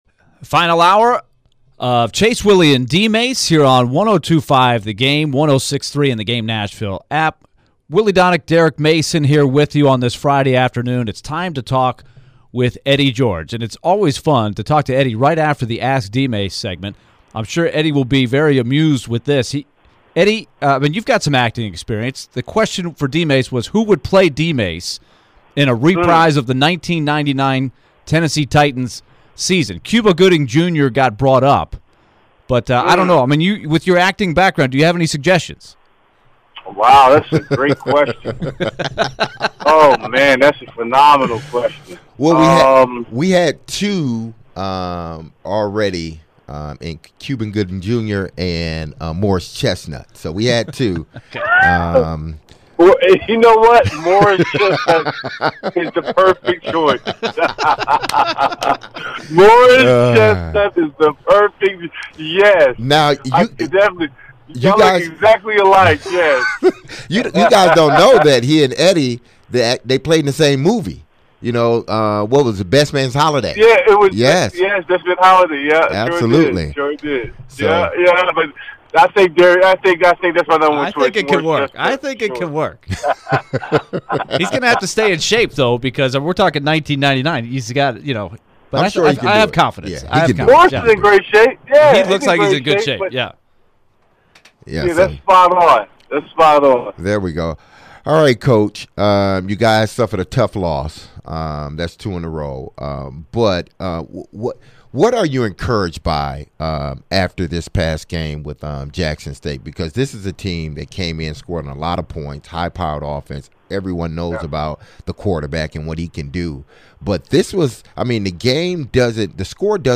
Eddie George TSU HC interview (9-16-22)